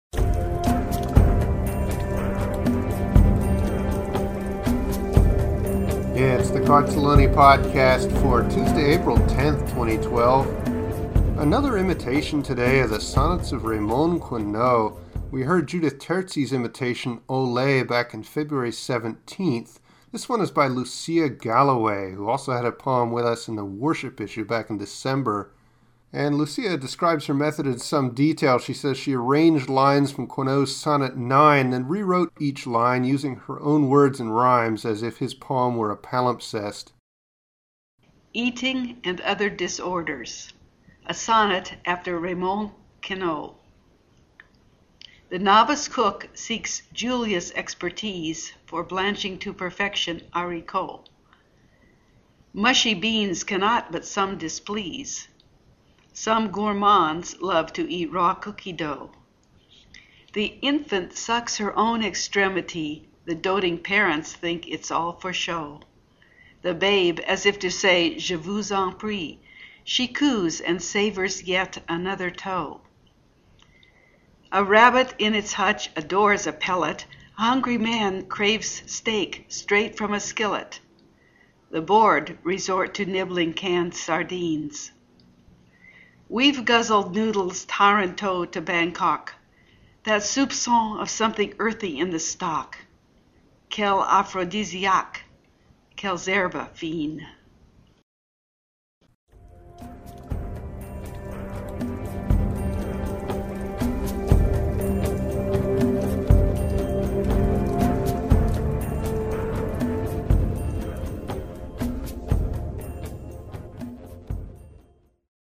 Love the French and your pronunciation is divine!
Fun, even jaunty!